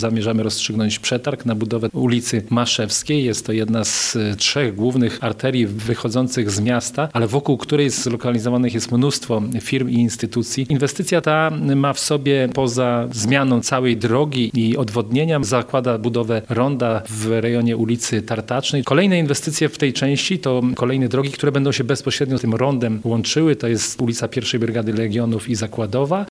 To właśnie na zapewnieniu jak najlepszych warunków przedsiębiorcom skupia się teraz goleniowski samorząd – mówi wiceburmistrz Goleniowa, Tomasz Banach